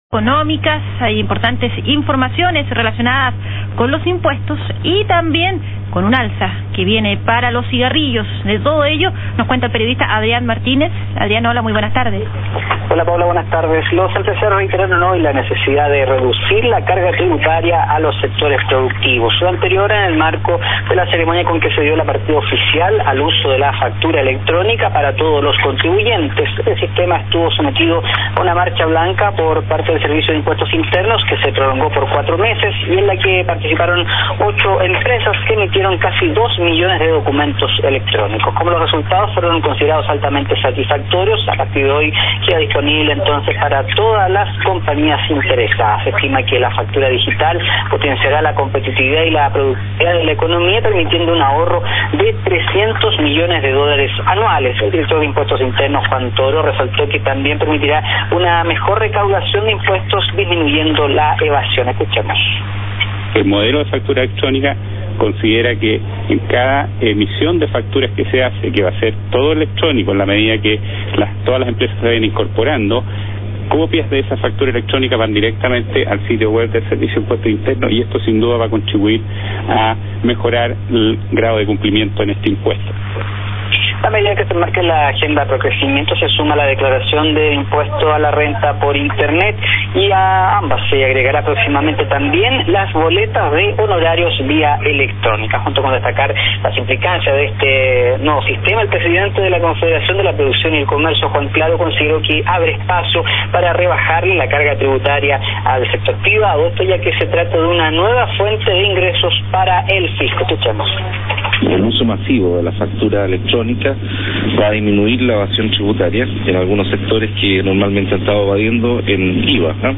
Medios Radiales